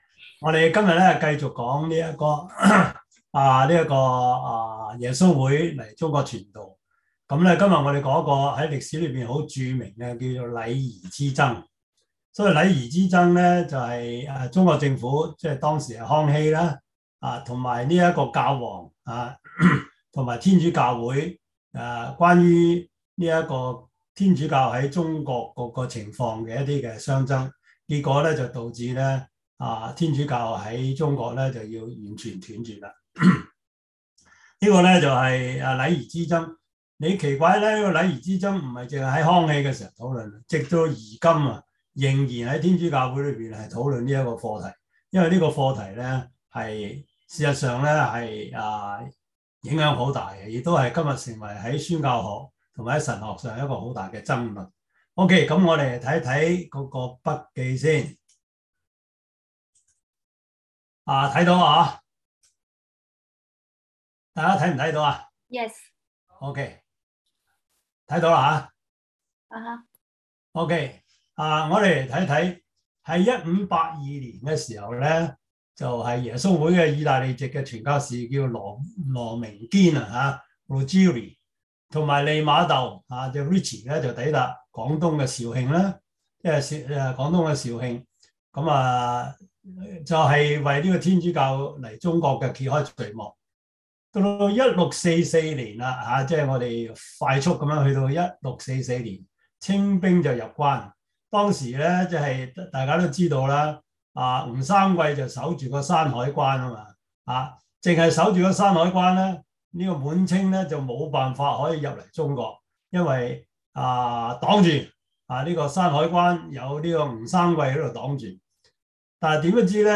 Service Type: 中文主日學